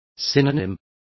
Complete with pronunciation of the translation of synonyms.